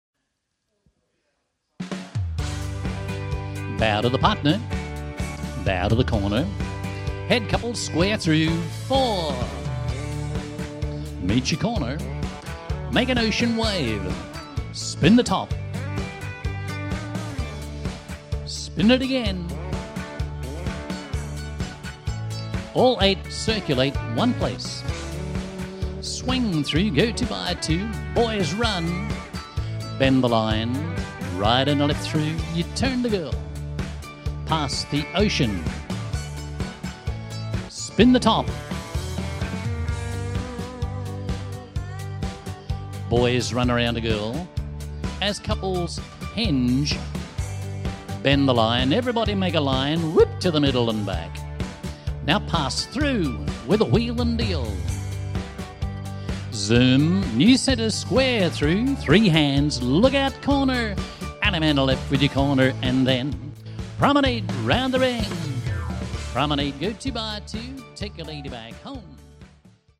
Square Dance Music